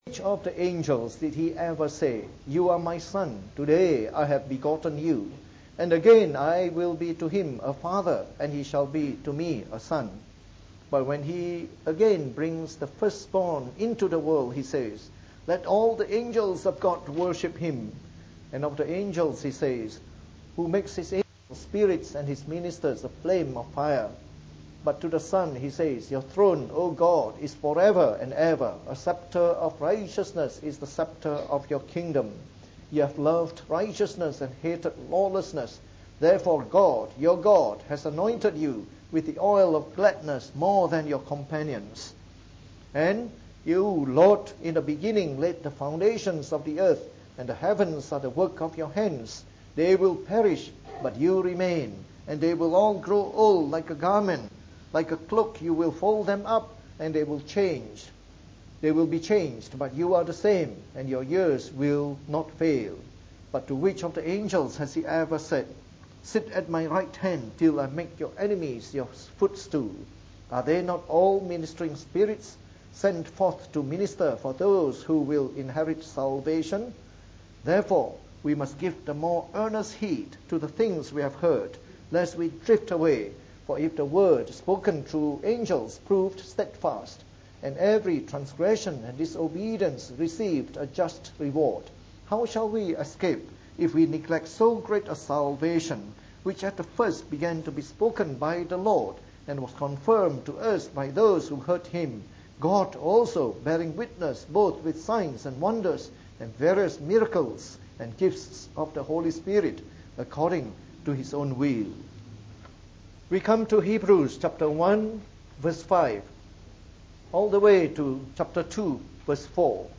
From our new series on the “Epistle to the Hebrews” delivered in the Evening Service.